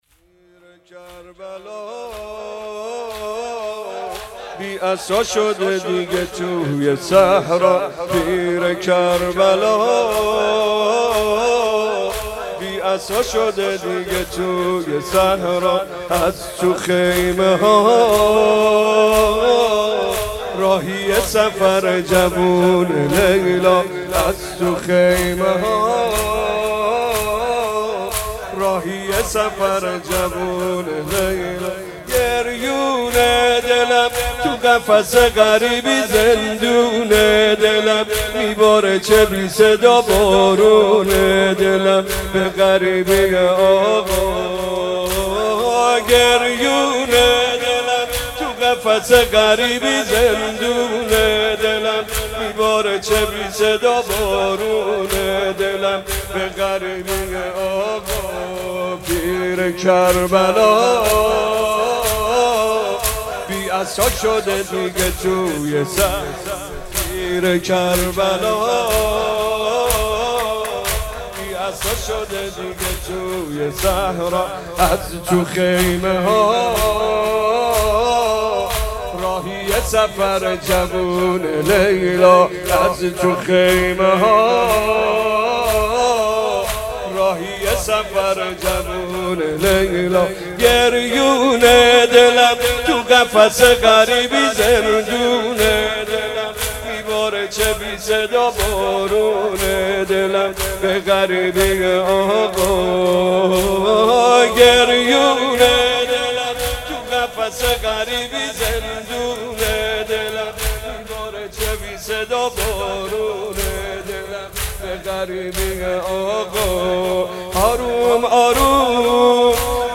مناسبت : شب هشتم محرم
مداح : محمدرضا طاهری قالب : زمینه